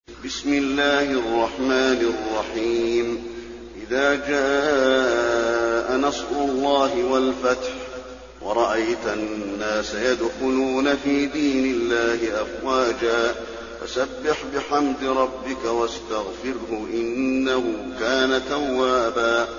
المكان: المسجد النبوي النصر